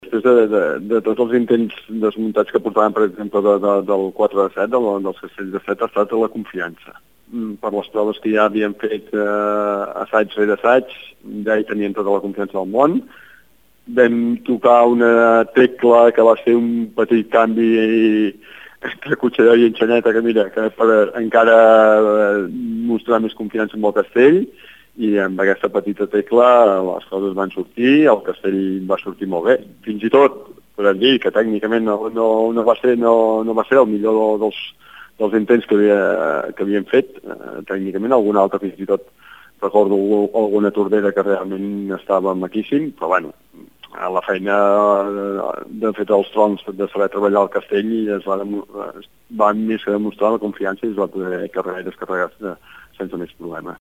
en declaracions a Ràdio Tordera ens explica que la clau per aconseguir aquestes construccions és la confiança.